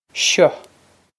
seo shuh
This is an approximate phonetic pronunciation of the phrase.